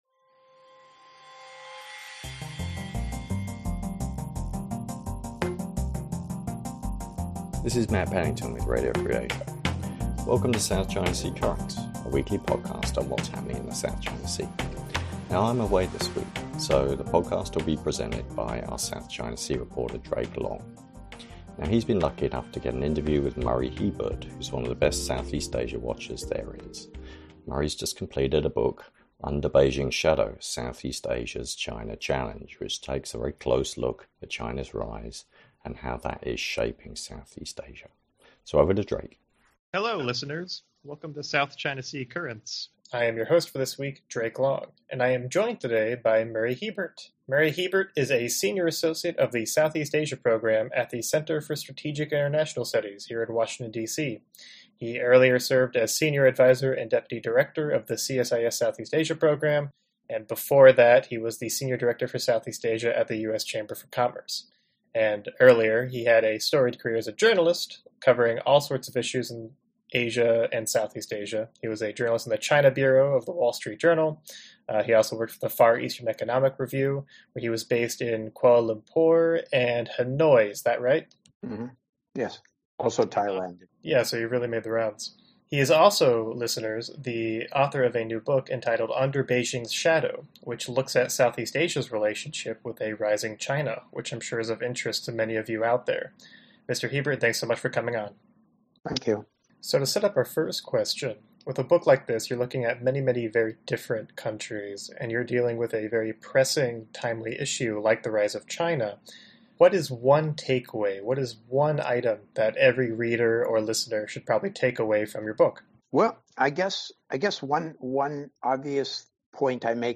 Southeast Asia Under Beijing’s Shadow: Interview